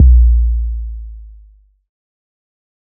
12kb - 808mafia.wav